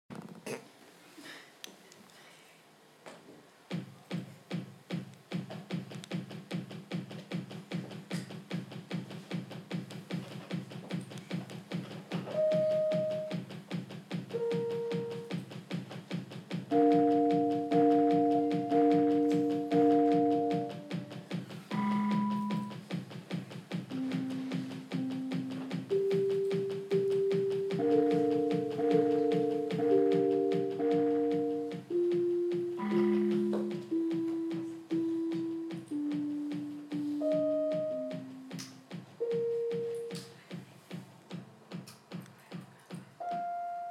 Our avant garde performance!